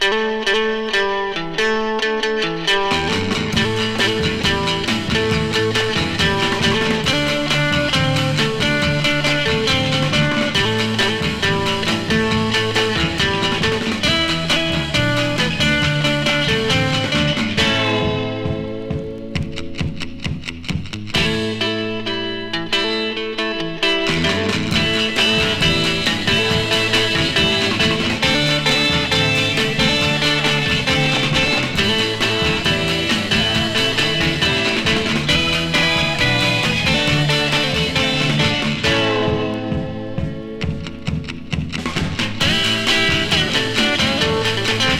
Rock'N'Roll, Surf　USA　12inchレコード　33rpm　Stereo